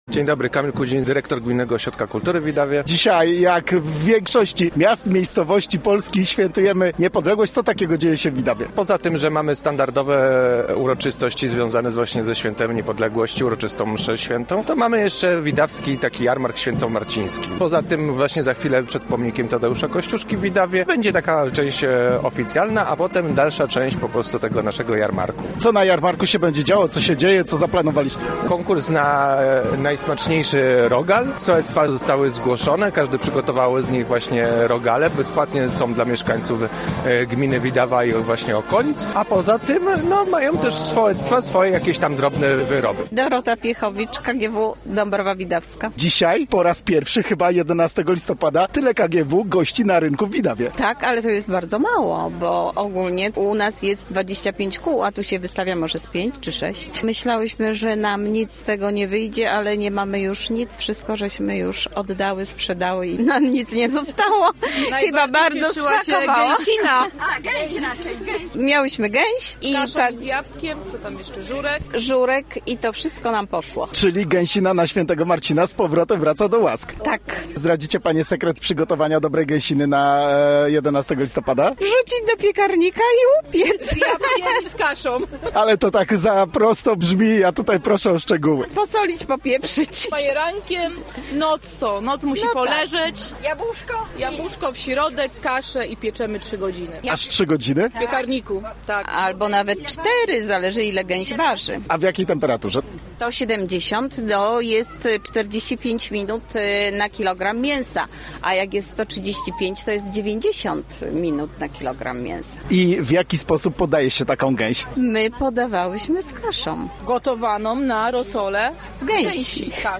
Jarmarkiem Świętomarcińskim uczcili w Widawie dzień 11 listopada (zdjęcia)